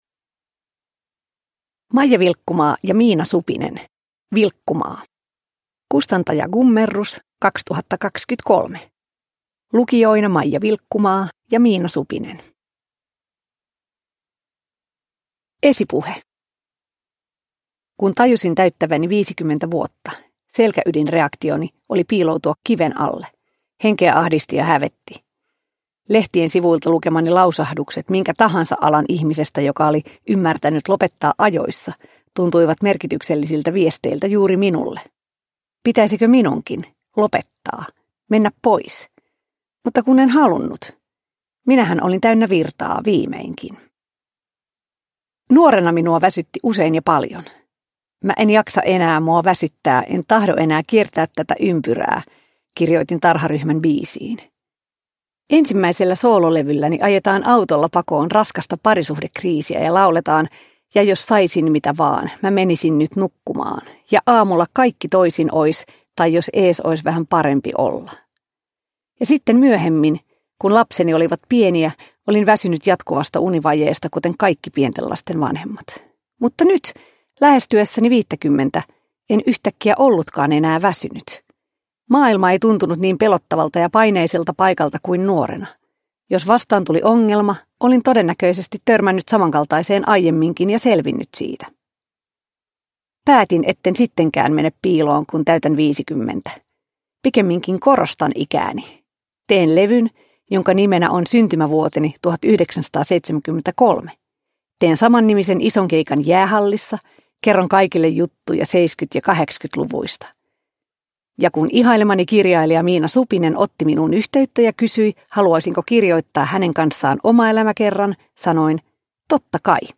Vilkkumaa – Ljudbok